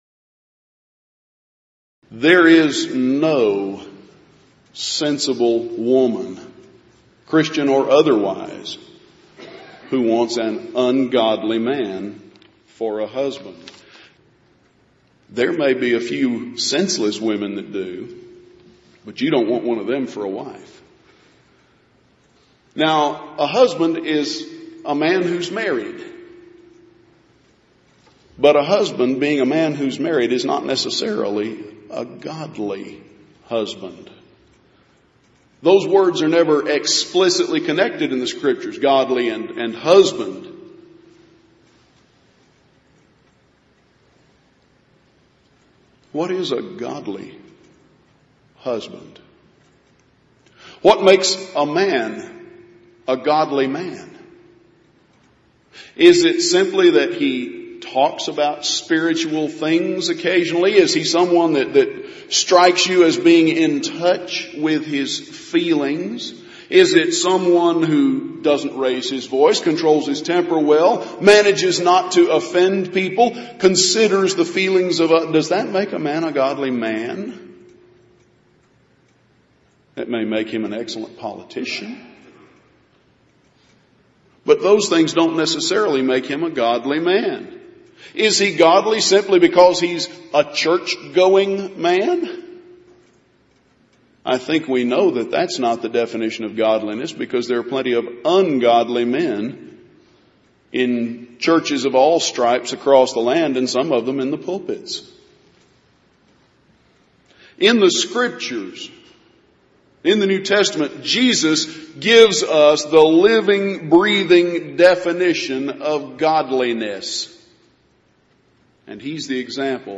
Event: 28th Annual Southwest Lectures
lecture